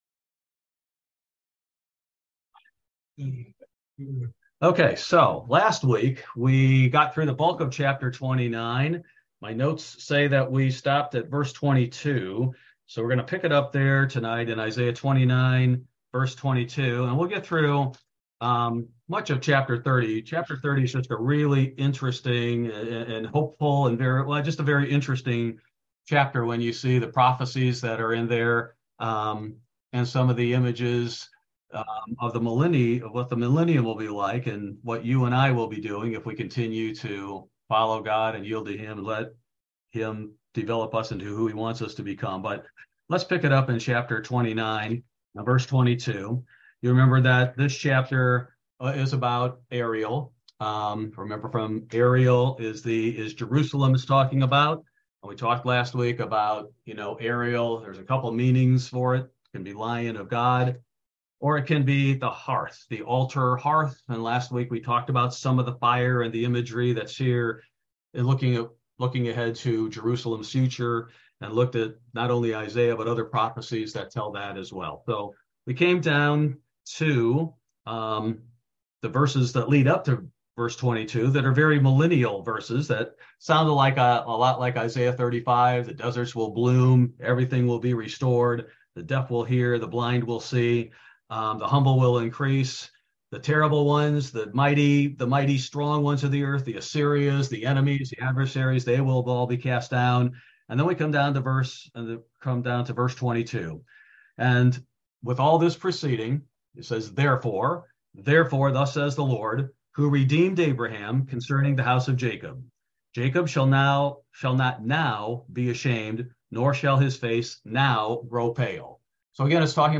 Bible Study: March 29, 2023